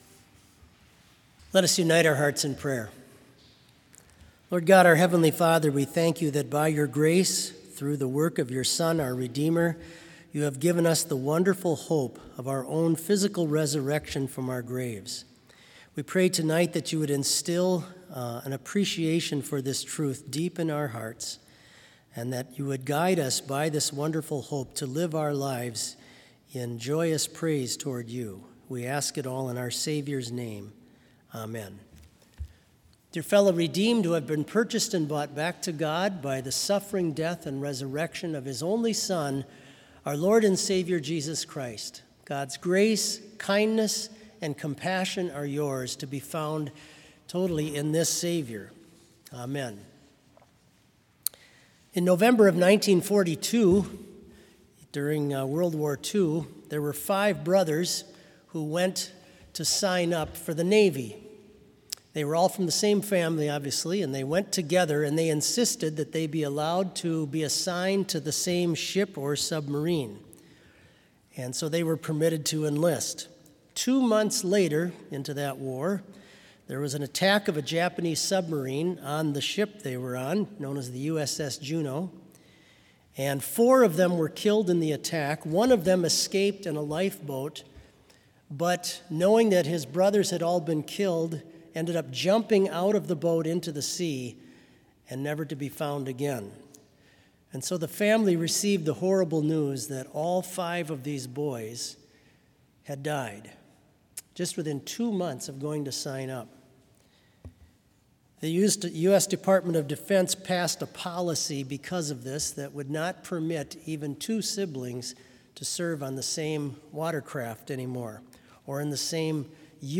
Complete Service
• Prelude
• Sermon
This Vespers Service was held in Trinity Chapel at Bethany Lutheran College on Wednesday, September 30, 2020, at 5:30 p.m. Page and hymn numbers are from the Evangelical Lutheran Hymnary.